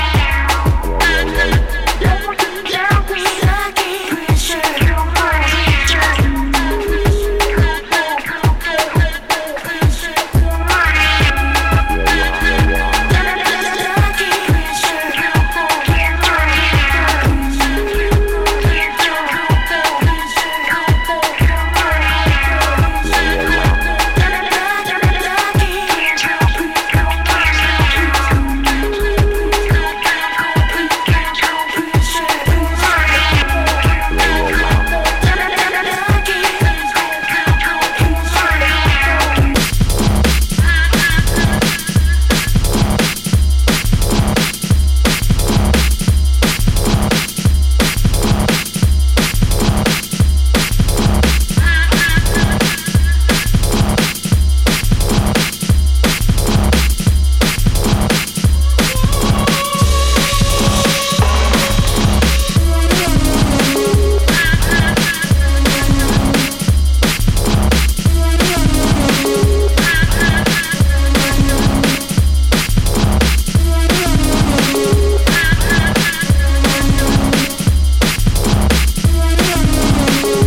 Remix
Hit Mix